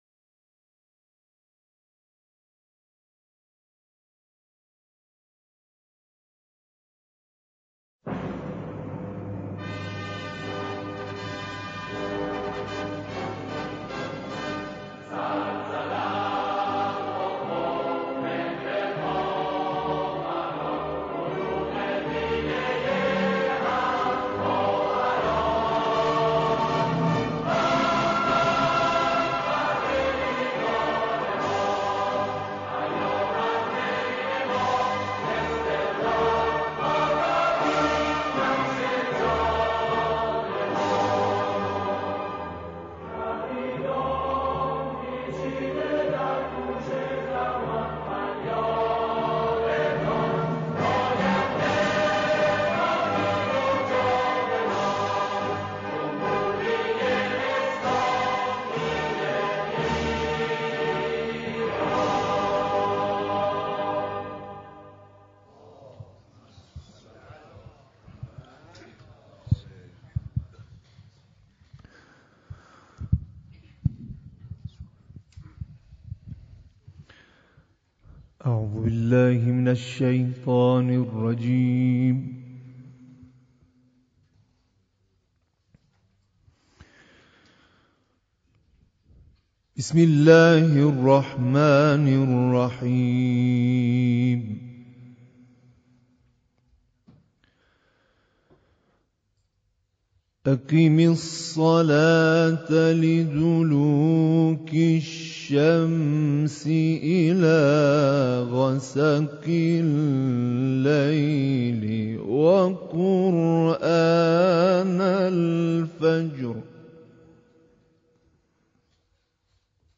رادیو انقلاب 11 بهمن با حضور حجت الاسلام سیدرمضان موسوی مقدم قایم مقام رسانه ملی، حمید شاه آبادی معاون صدا و جمعی از مدیران رادیو در استودیو شماره 29 رادیو راه اندازی شد.